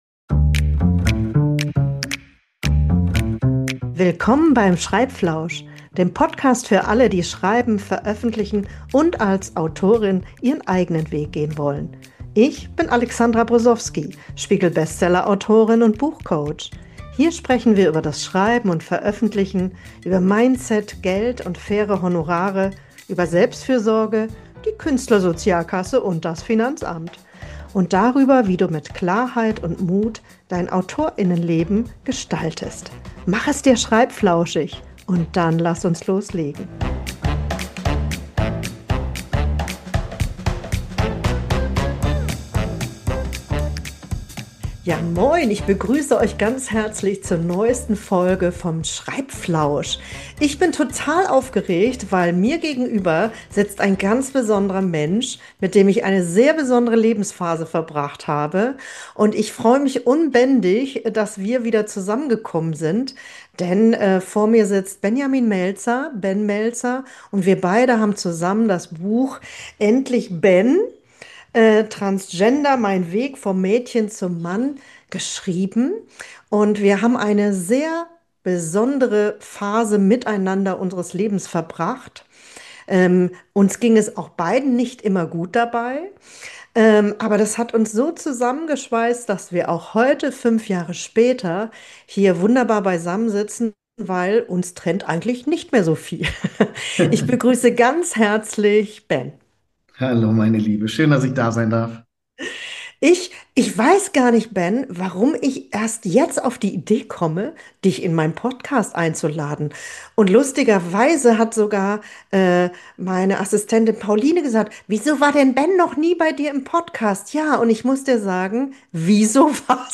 Das Thema ist schwer, aber wir haben in unserem Interview viel gelacht. Wir erzählen auch, was uns richtig sauer gemacht hat und warum wir diesen Vertrag nicht noch einmal so unterzeichnen würden.